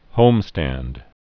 (hōmstănd)